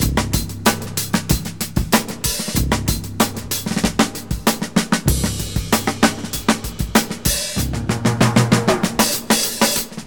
• 127 Bpm Drum Loop C Key.wav
Free breakbeat sample - kick tuned to the C note.
127-bpm-drum-loop-c-key-epz.wav